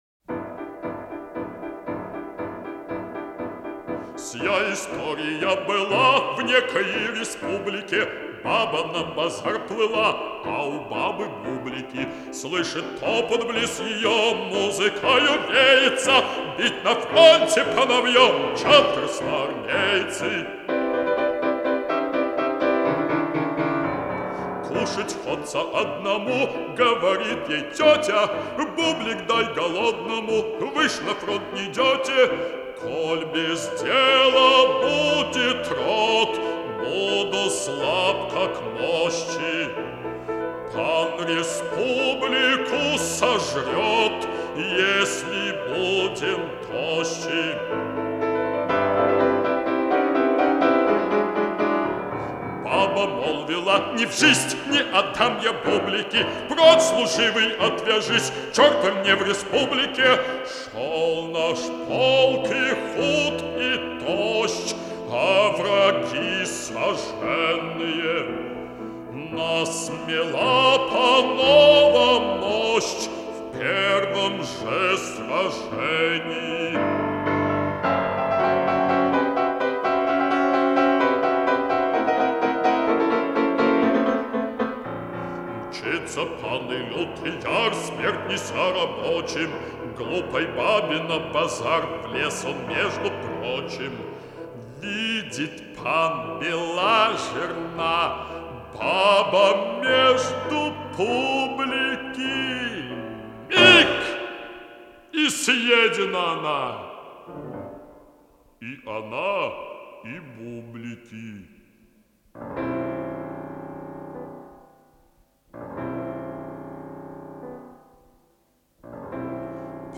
Mūzikas ieraksts